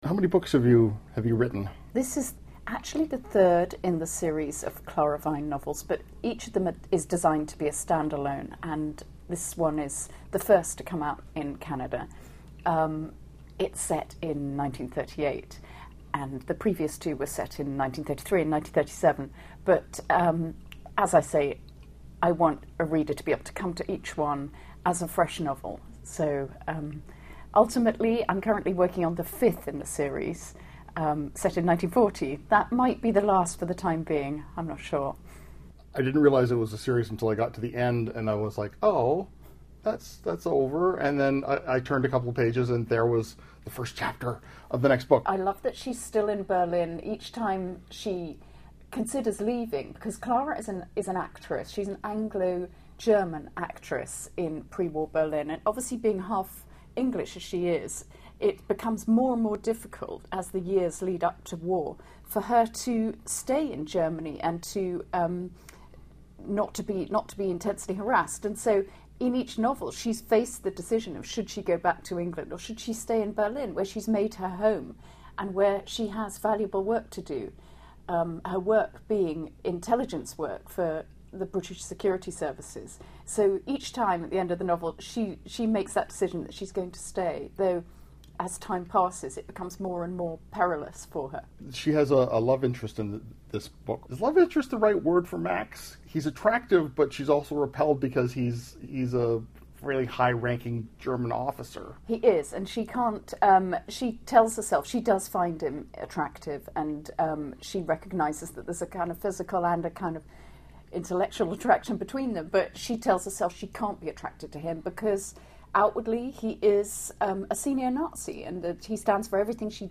Recording Location: Toronto
Type: Interview